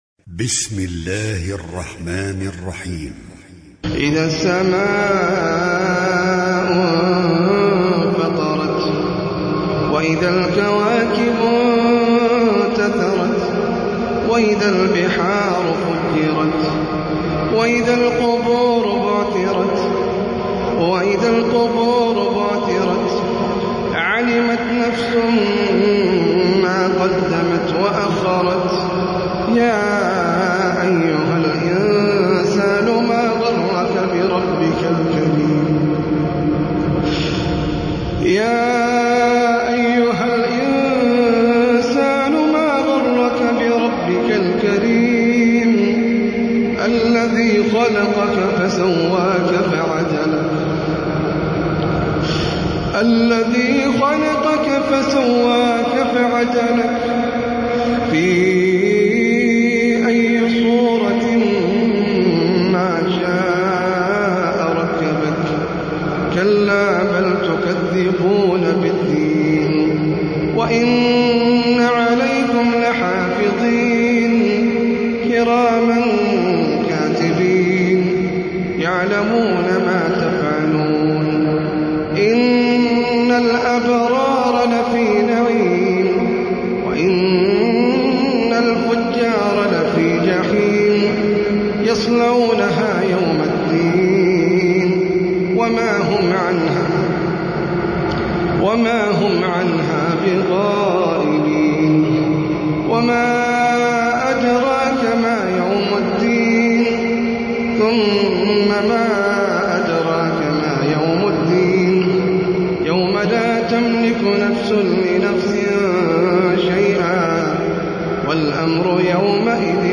سورة الانفطار - المصحف المرتل (برواية حفص عن عاصم)
جودة عالية